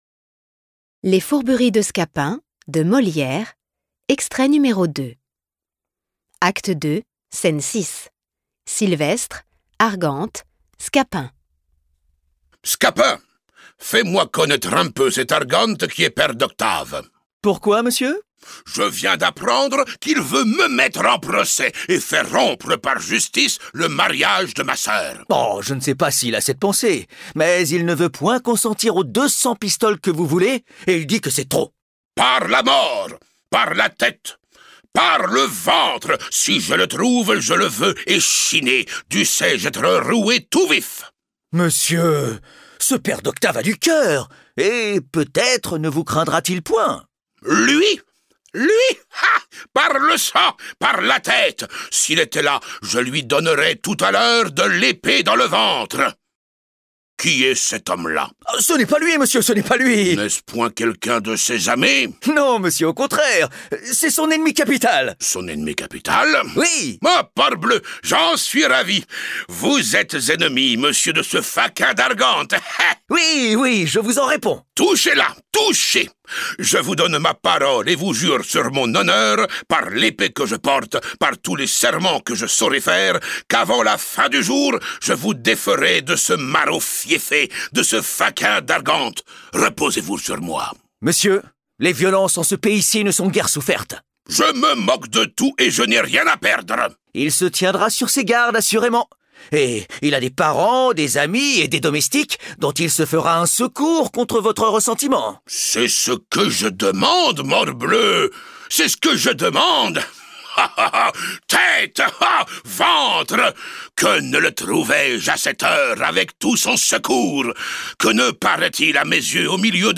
Les Fourberies de Scapin, de Molière • Acte II, scène 6 • lignes 1 à 88 (2e extrait lu)